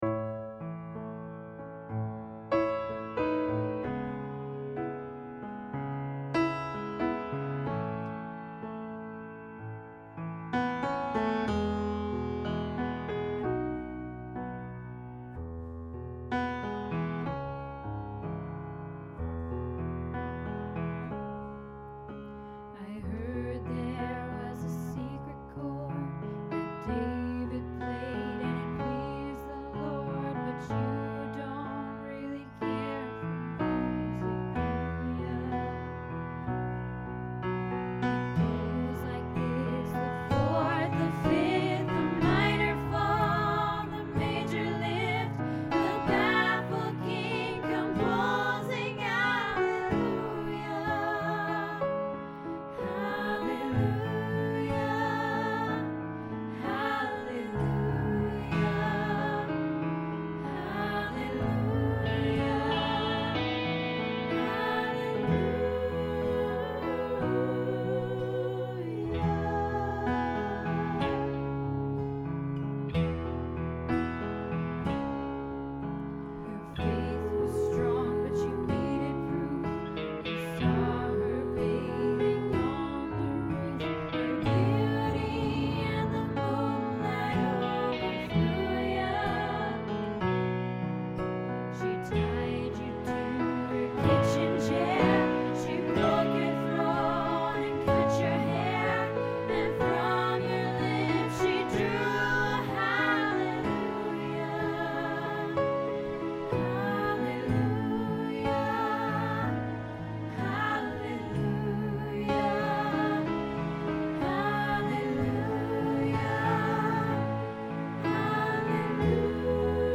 Hallelujah Soprano